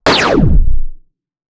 added weapon sounds
laser.wav